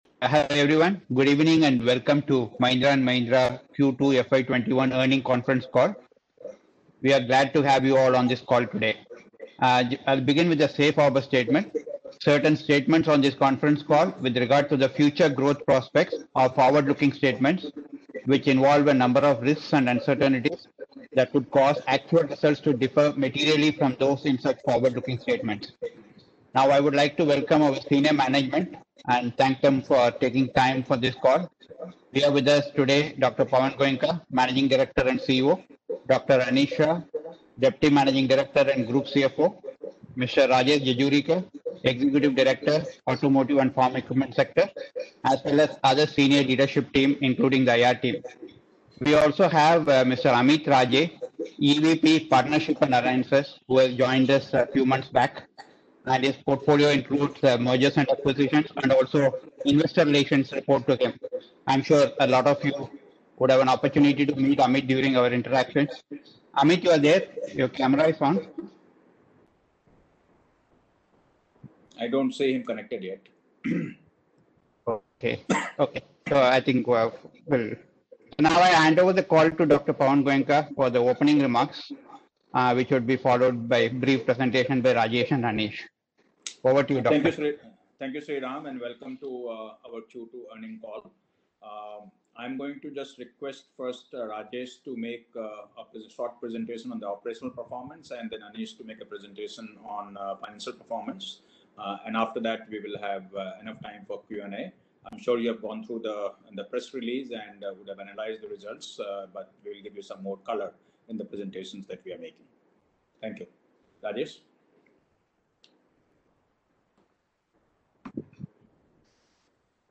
Earnings-call-11-NOV-Audio-1.mp3